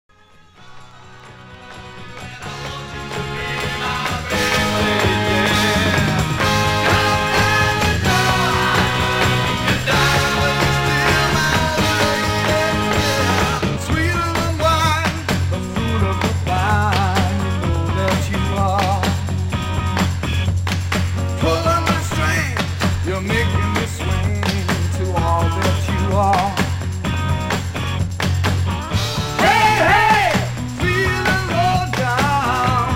それまでのキャッチーさもやや復活させたような作風。